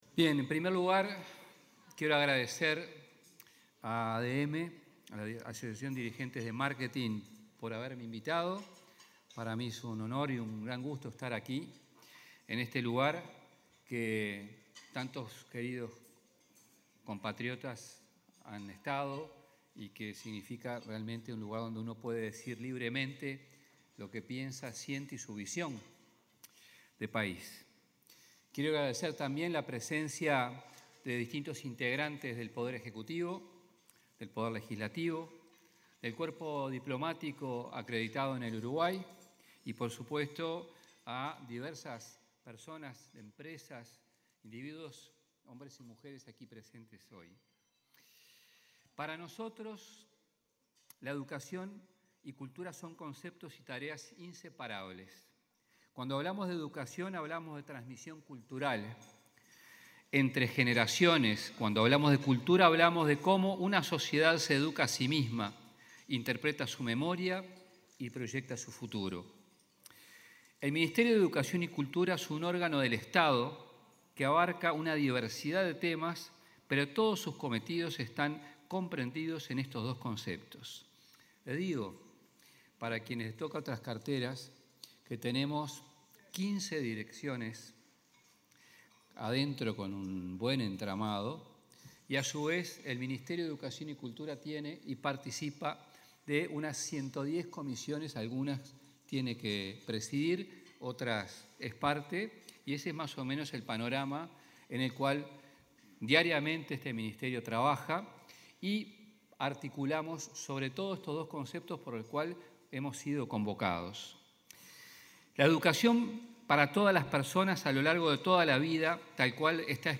Palabras del ministro de Educación y Cultura, José Carlos Mahía
Palabras del ministro de Educación y Cultura, José Carlos Mahía 29/10/2025 Compartir Facebook X Copiar enlace WhatsApp LinkedIn En el almuerzo de trabajo Educación y Cultura: Desafíos y Oportunidades para un País que Crece, organizado por la Asociación de Dirigentes de Marketing (ADM), disertó el ministro de Educación y Cultura, José Carlos Mahía.